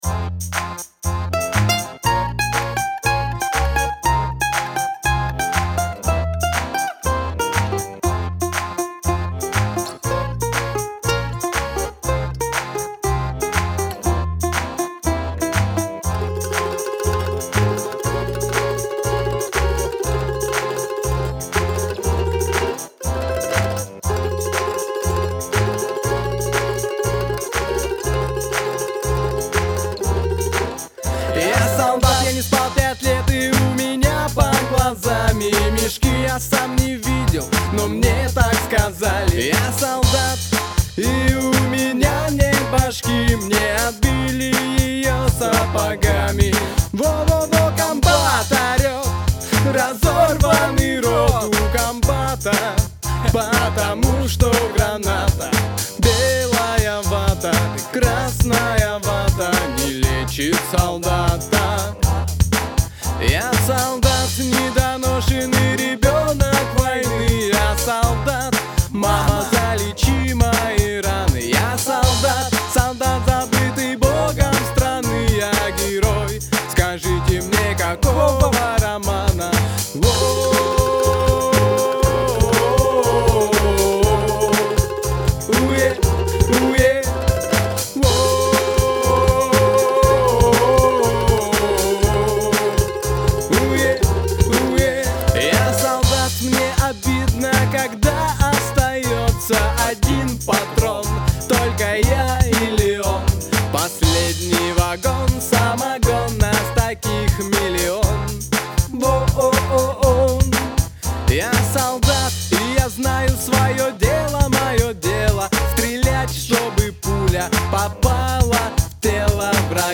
Прошу оценить сведение.